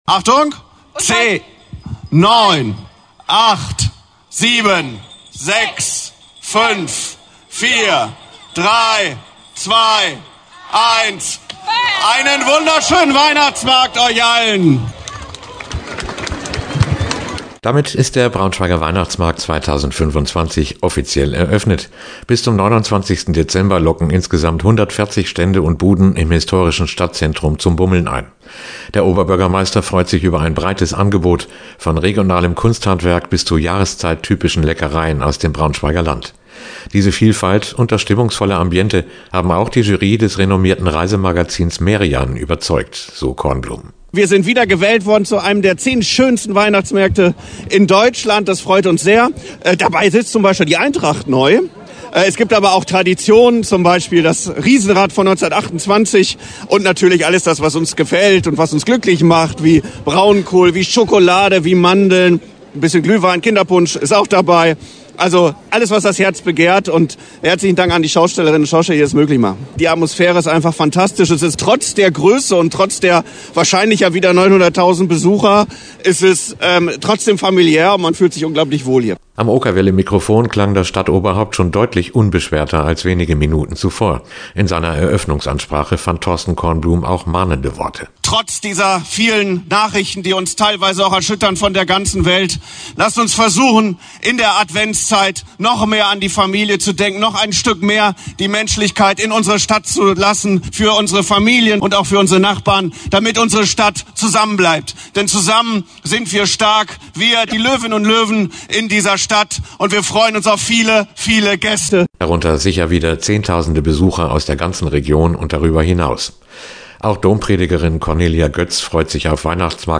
Ein Radiointerview findet statt.